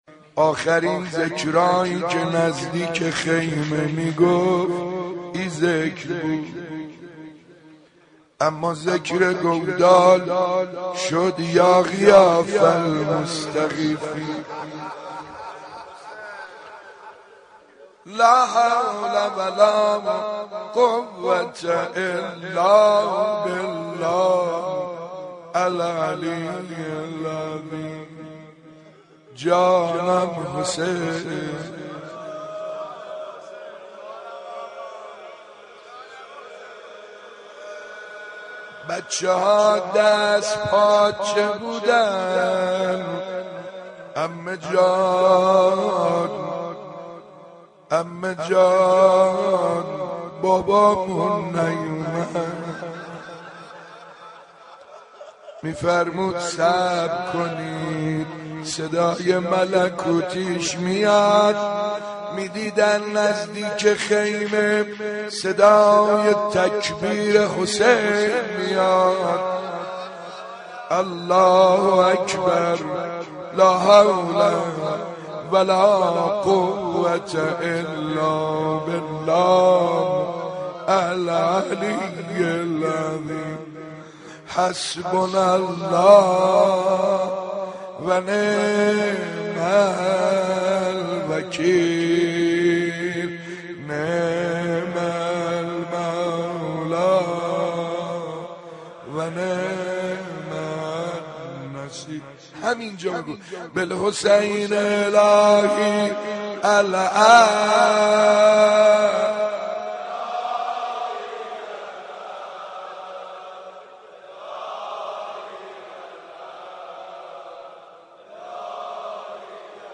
ذکر مصیبت ورود کاروان امام حسین(ع) به کربلا